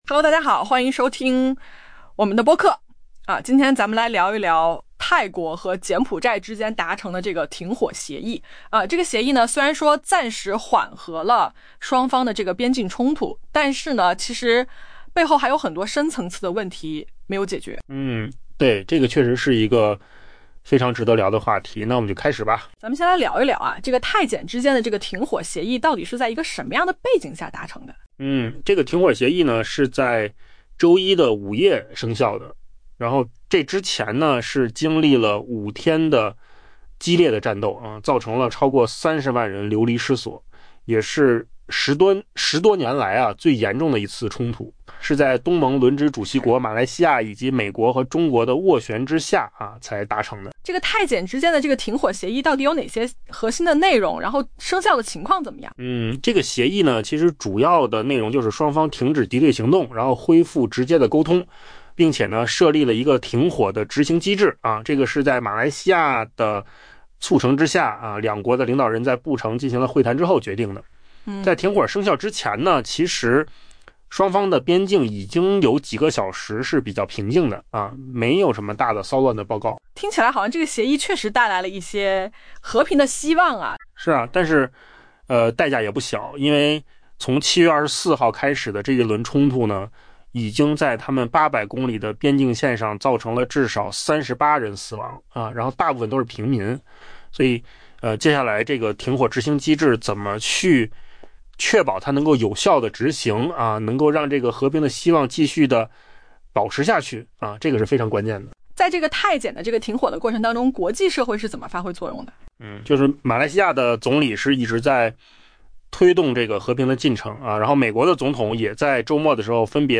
AI博客：换个方式听新闻 下载mp3
音频由扣子空间生成